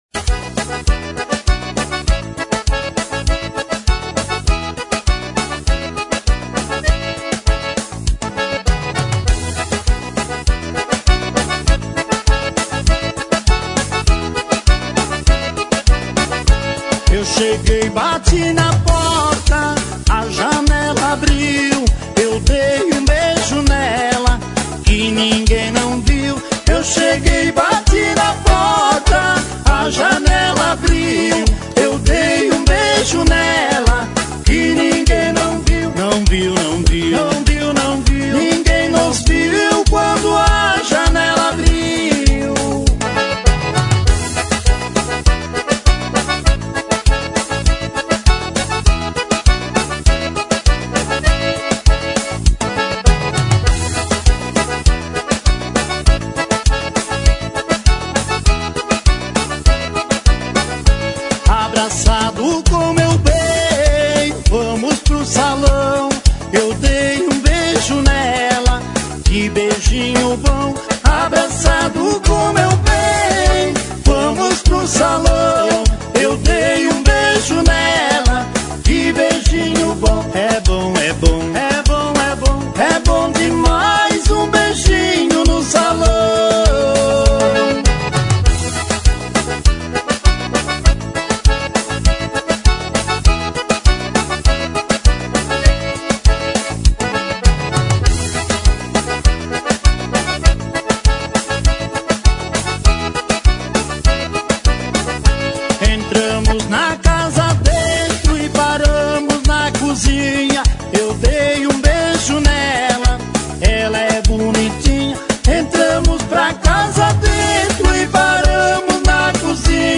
Sertanejas Para Ouvir: Clik na Musica.